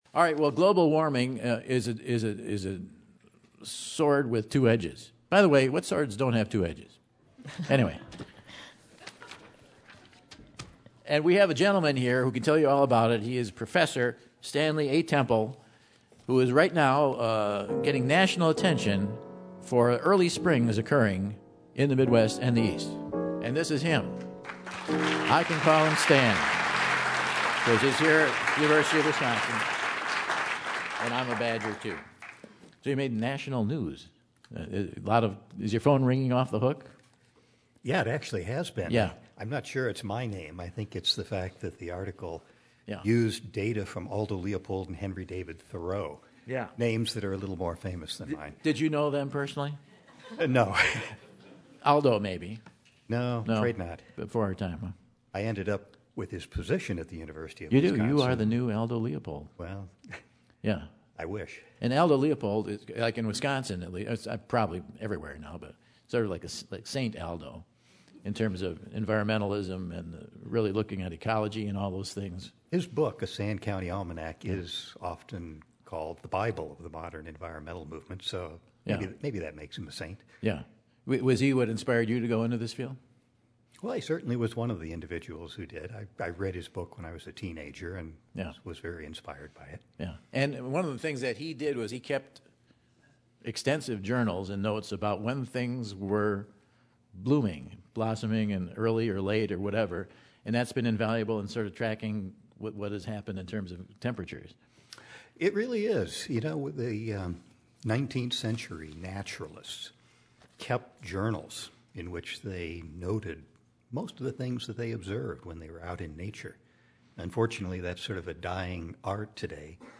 on the Terrace stage to chat about the dangers of the early bloom.